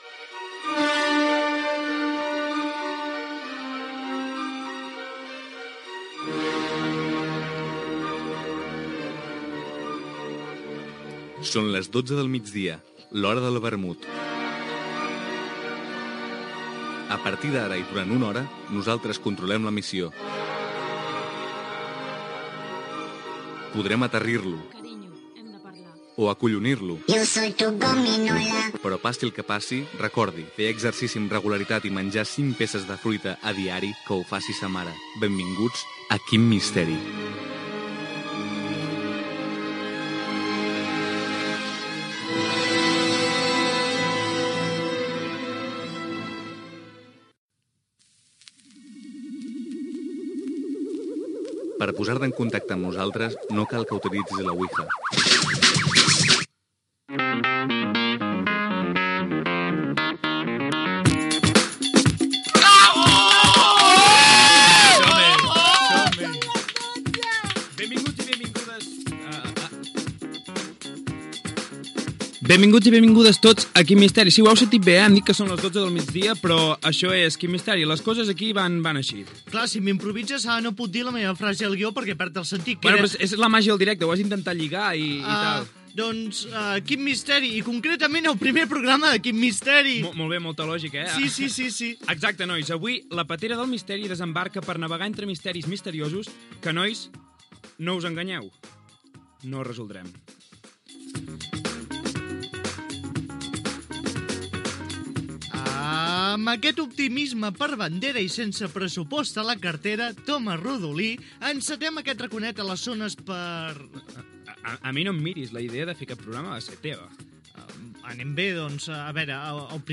Sintonia, hora, benvinguda al primer programa, declaració d'intencions, presentació de l'equip, formes de contactar amb el programa, notícia curiosa
Entreteniment